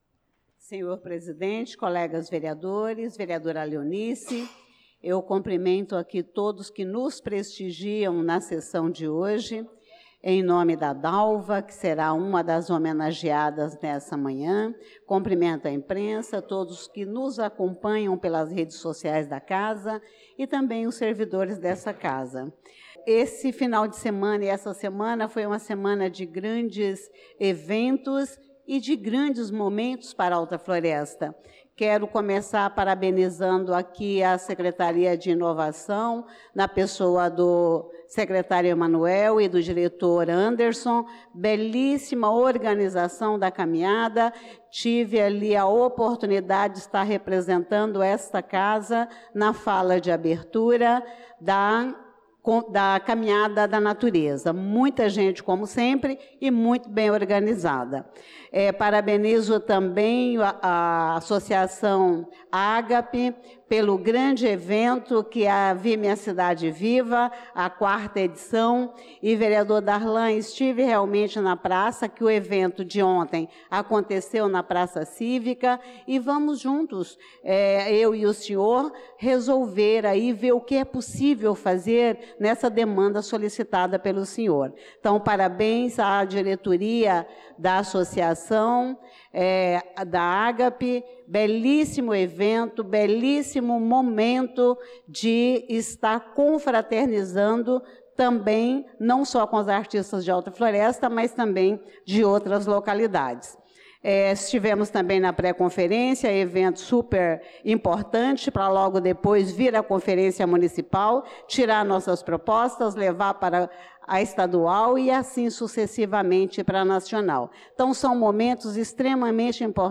Pronunciamento da vereadora Elisa Gomes na Sessão Ordinária do dia 26/05/2025